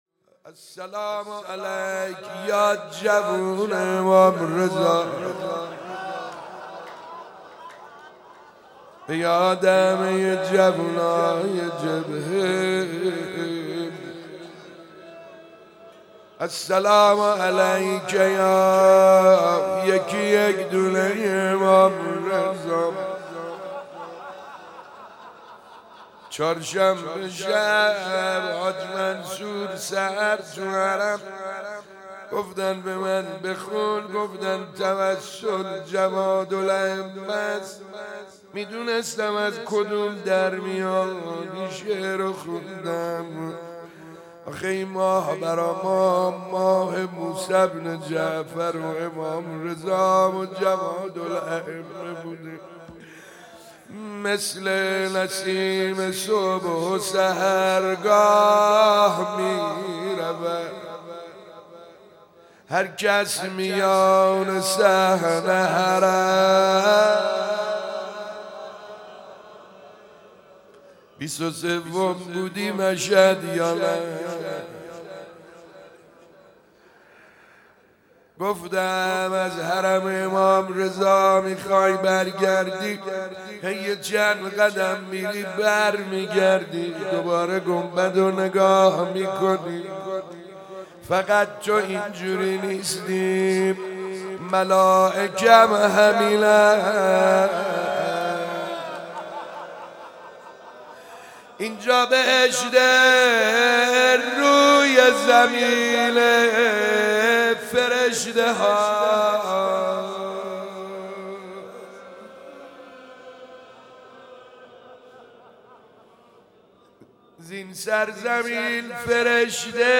روضه امام جواد